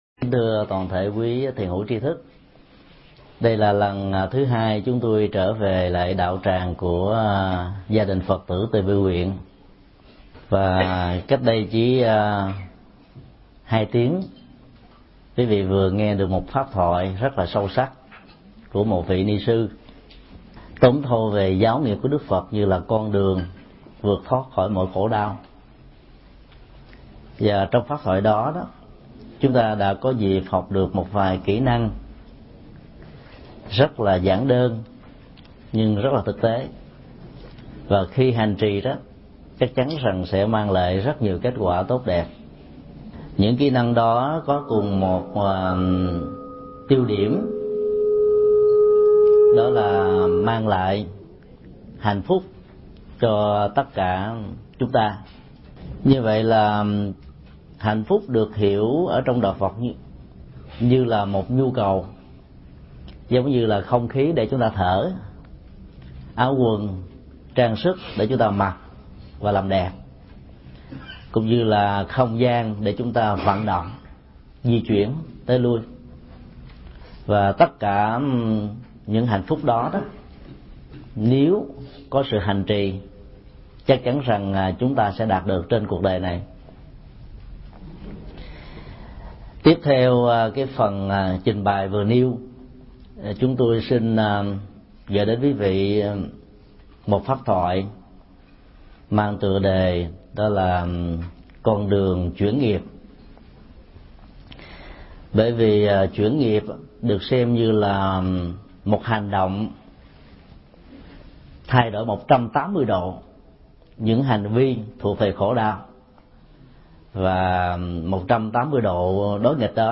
Mp3 Thuyết Giảng Chuyển nghiệp 1